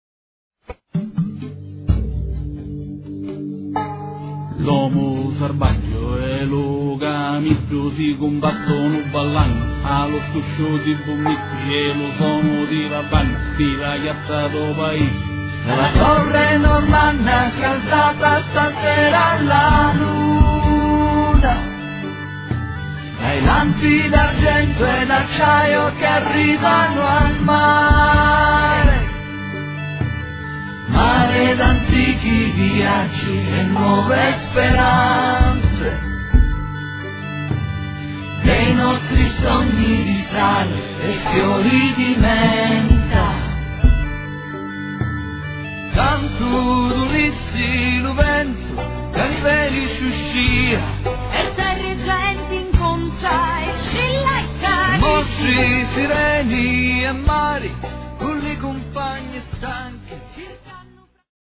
opera poetico musicale in un solo atto